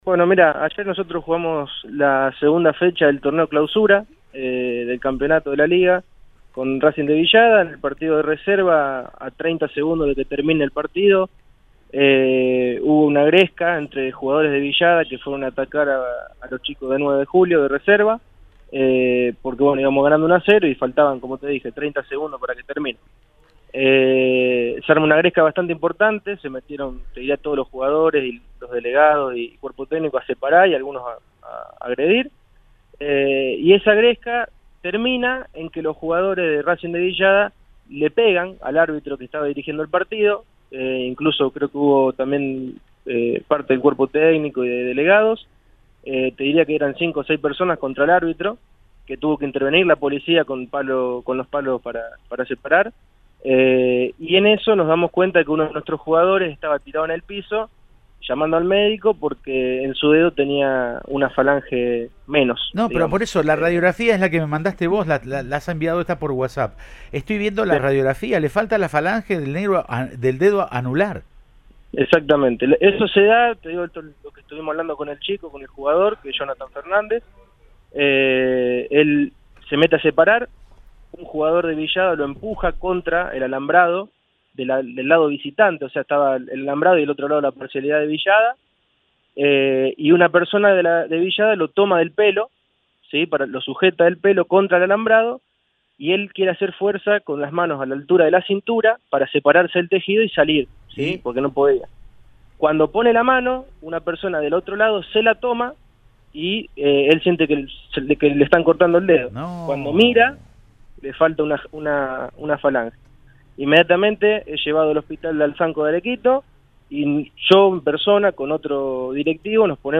Audio. Un dirigente contó detalles de los incidentes que hubo en el partido